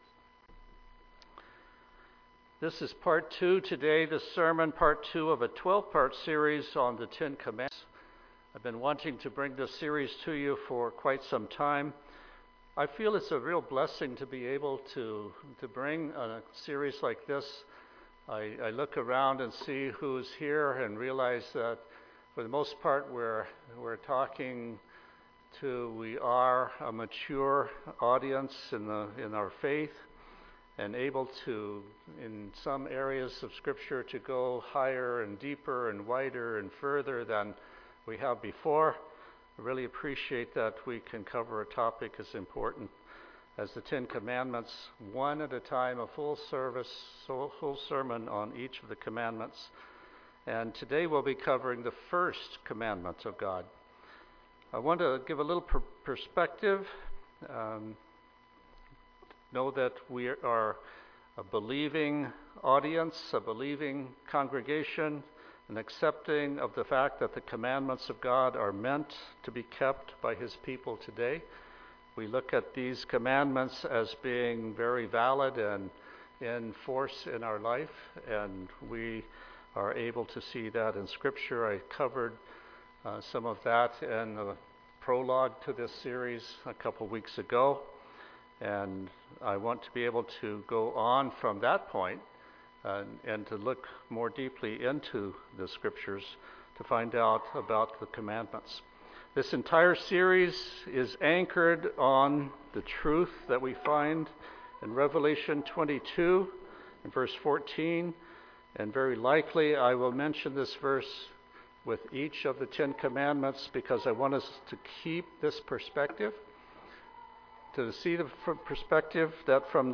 Sermons
Given in Tacoma, WA